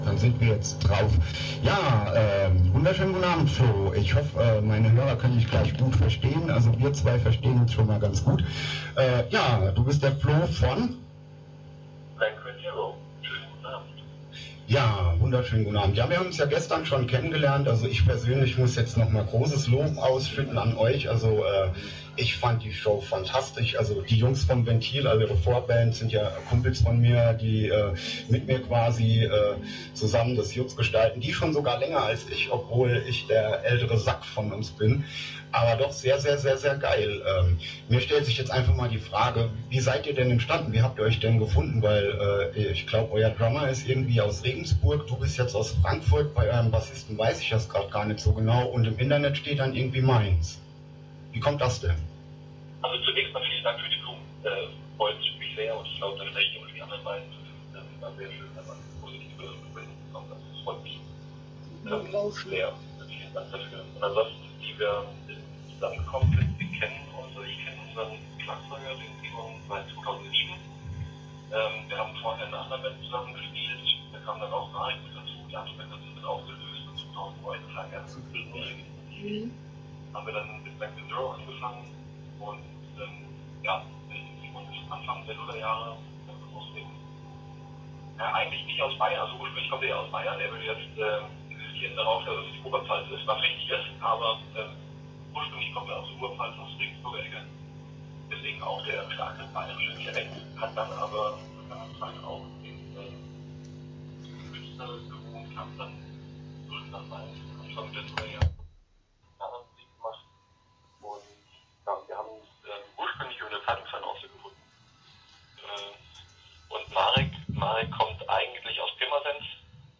Start » Interviews » Blank When Zero